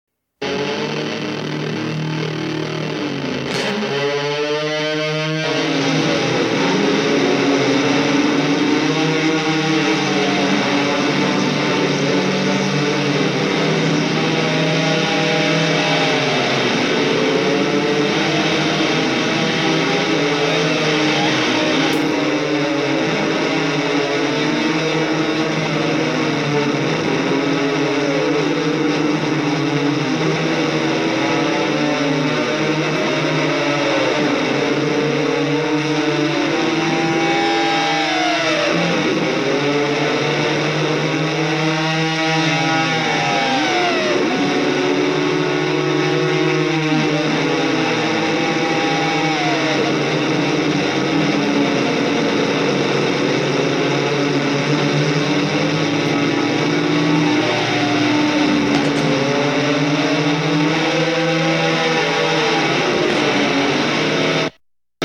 Жанр: Alternative Rock